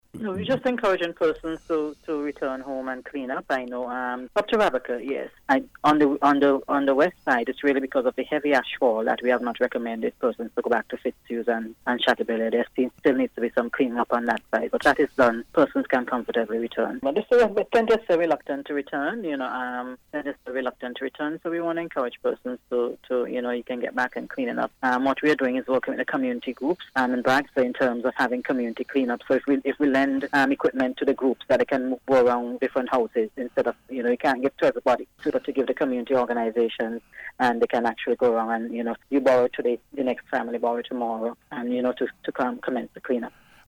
The encouragement came from Director of the National Emergency Management Organization NEMO Michelle Forbes while speaking on NBC Radio’s Eyeing La Soufreiere program earlier this week.